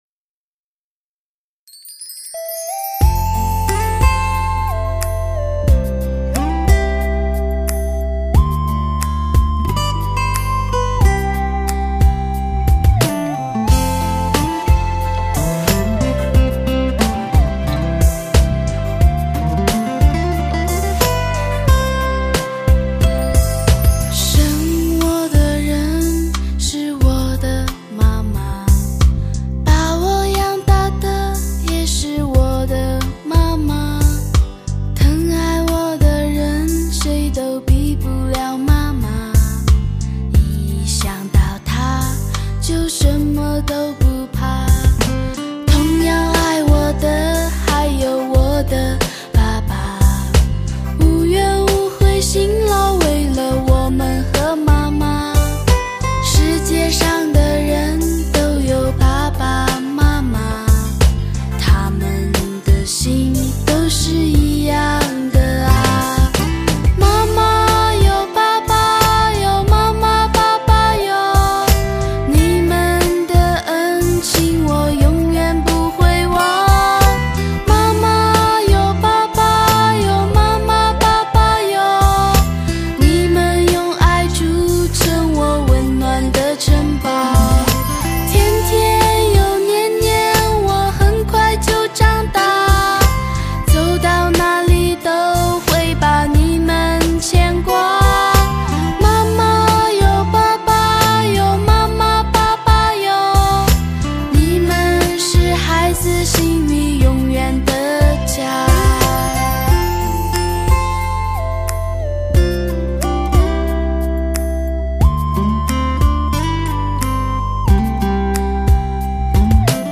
风格类型: 天籁人声 /Pop
不一样的嗓音，不一样的经典情歌。